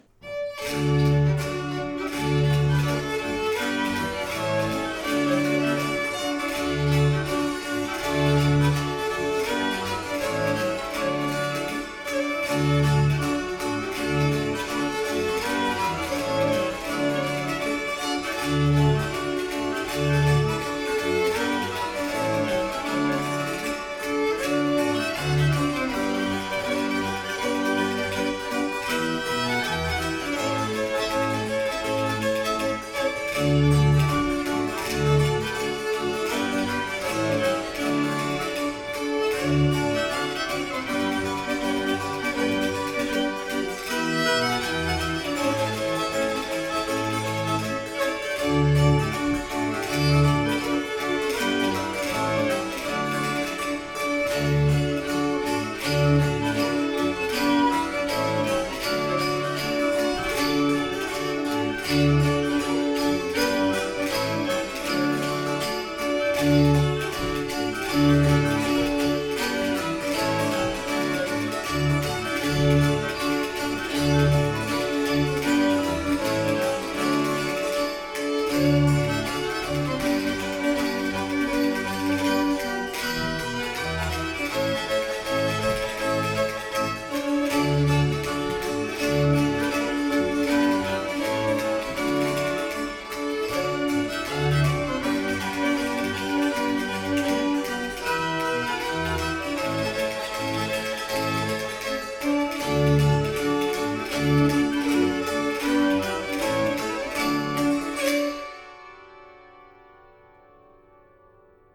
Alpbarock: Danse d’Appenzell en ré.